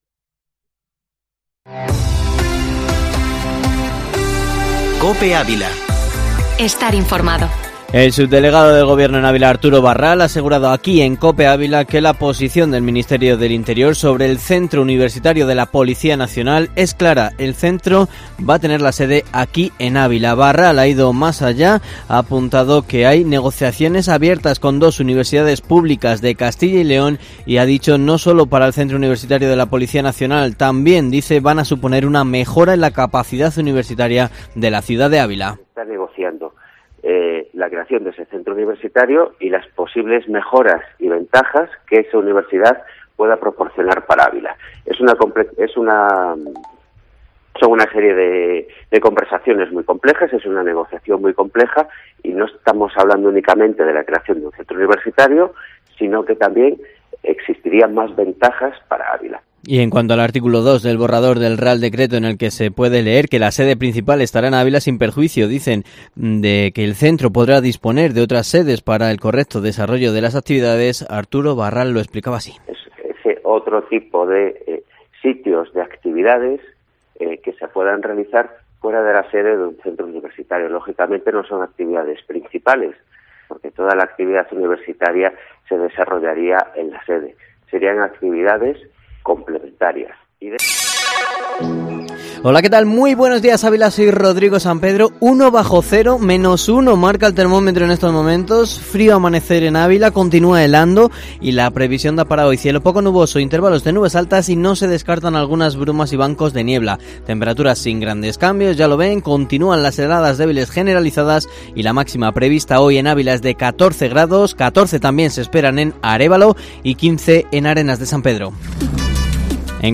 Informativo matinal Herrera en COPE Ávila 10/03/2021